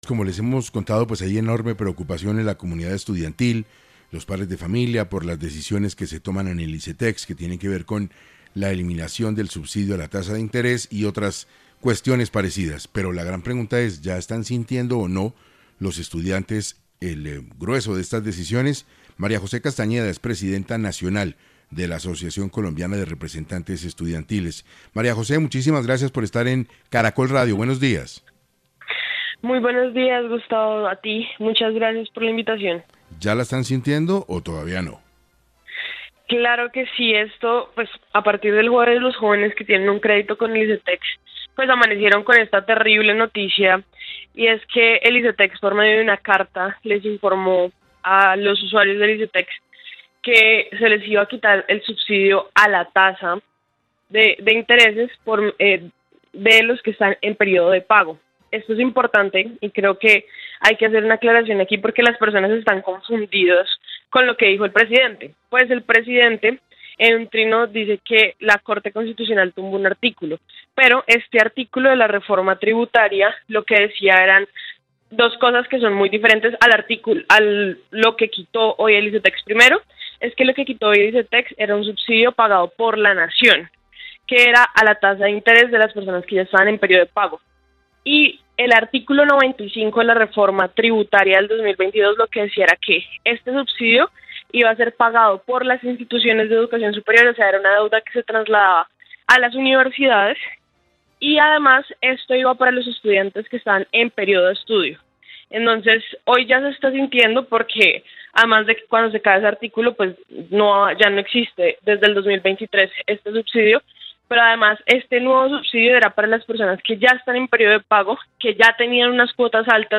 Petro debe responderle a los estudiantes por lo prometido en campaña y las ayudas del Icetex: ACREES | 6AM Hoy por Hoy | Caracol Radio